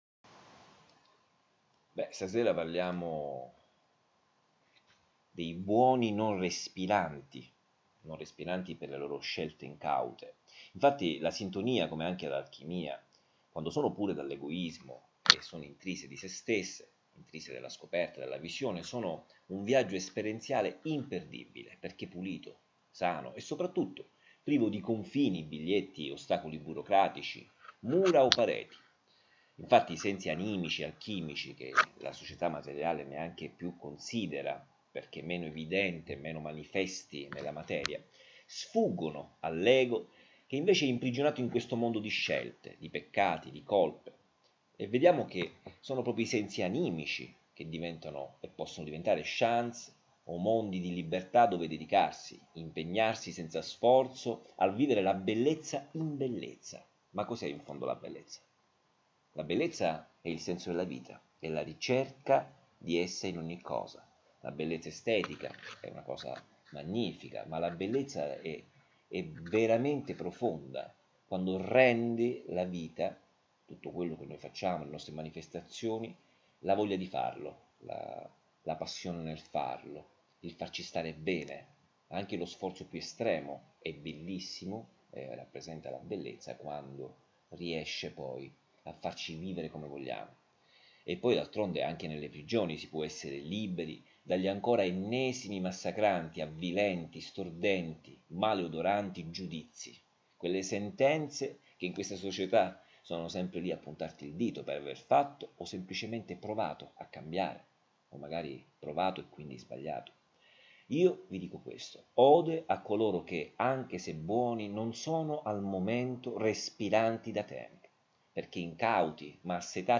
2 MINUTI D’AUDIO Riproducono la riflessione dell’articolo a voce alta, perché abbiano accesso all’ascolto i ciechi,  chi lavorando non ha tempo o chi preferisce ascoltare, e quelli tra noi che pur avendo la vista sono diventati i veri Non Vedenti.